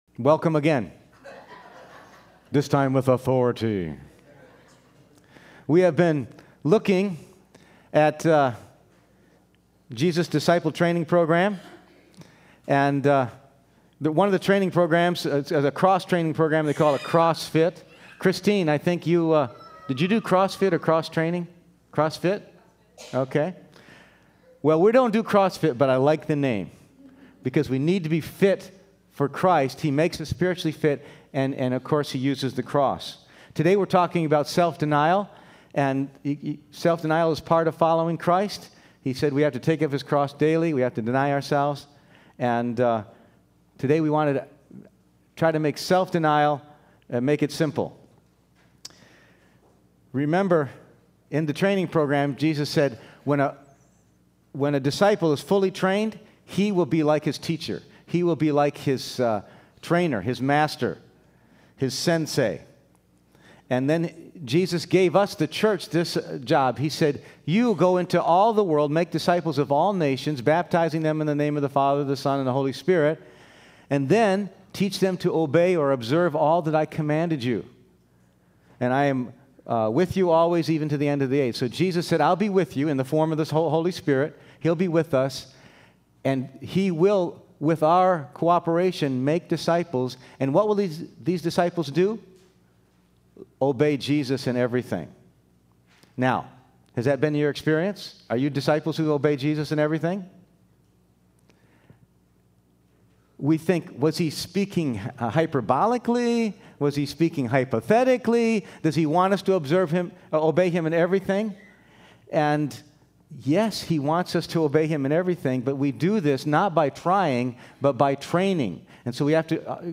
Passage: Matthew 13:44-48, Luke 9:18-26, Luke 14:28-33, 2 Corinthians 5:14-15 Service Type: Sunday Morning